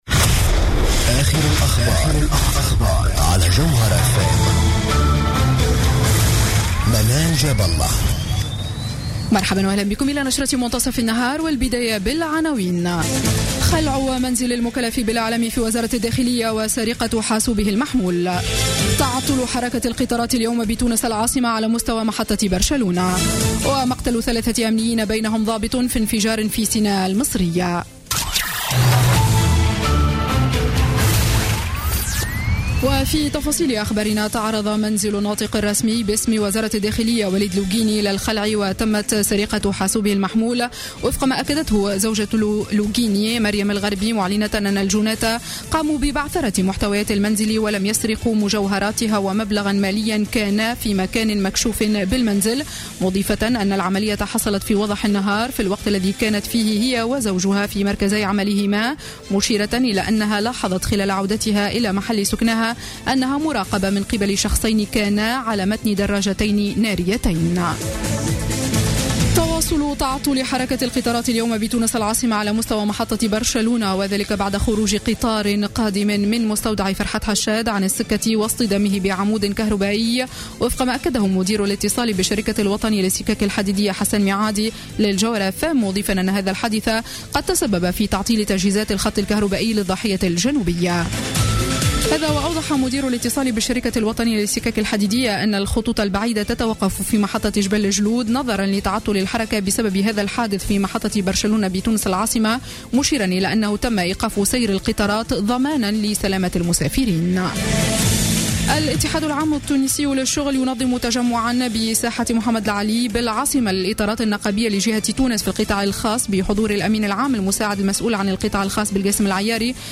نشرة أخبار منتصف النهار ليوم السبت 24 أكتوبر 2015